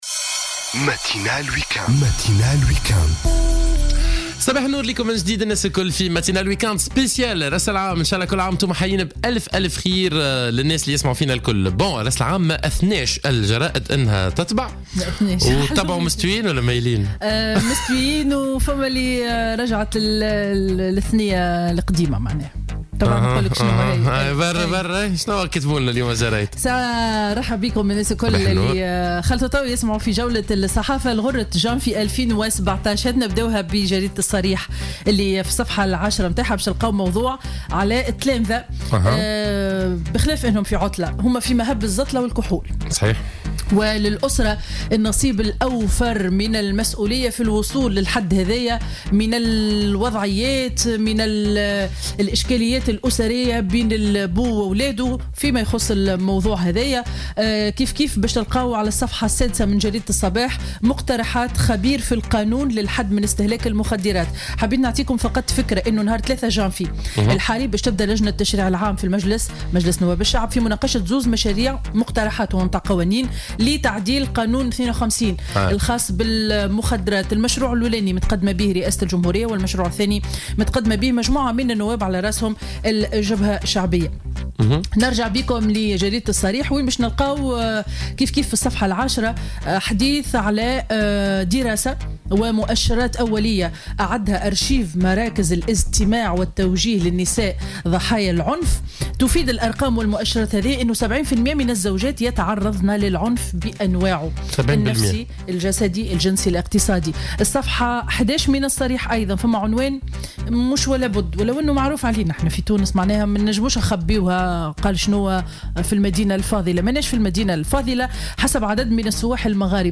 Revue de presse du Dimanche 1er Janvier 2017